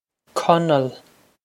Conall Kuh-nul
Pronunciation for how to say
This is an approximate phonetic pronunciation of the phrase.